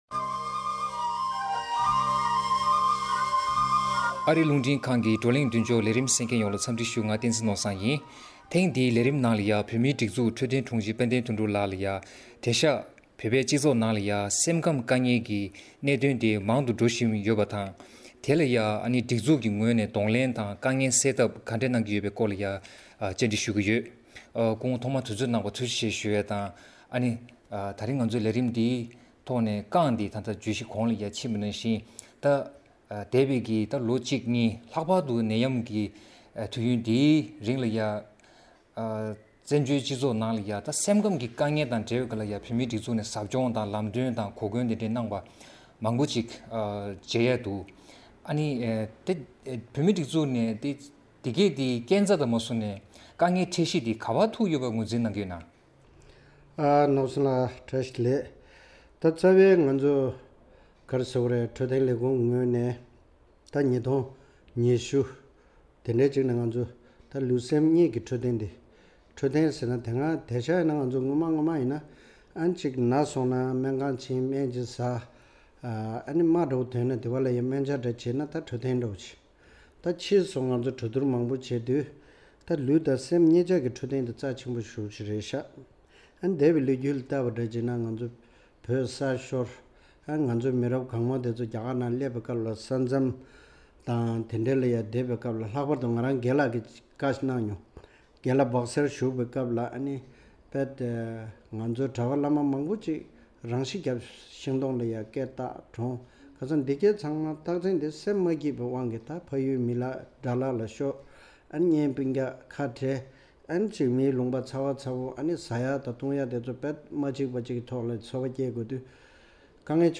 བཙན་བྱོལ་བོད་མིའི་སྤྱི་ཚོགས་ནང་སེམས་ཁམས་བདེ་ཐང་སྐོར་ལ་ཟབ་སྦྱོང་དང་སློབ་གསོའི་ལས་འགུལ་མང་དུ་འགྲོ་བཞིན་ཡོད་པ་དང་། ཐེངས་འདིའི་བགྲོ་གླེང་མདུན་ཅོག་ལས་རིམ་གྱིས་སེམས་ཁམས་བདེ་ཐང་གི་དཀའ་ངལ་དེ་བཙན་བྱོལ་བོད་མིའི་སྤྱི་ཚོགས་ནང་གདོང་ལེན་བྱེད་དགོས་པའི་གནད་དོན་ཡིན་མིན་སོགས་ཀྱི་སྐོར་ལ་གླེང་མོལ་ཞུས་ཡོད།